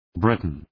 Προφορά
{‘brıtən}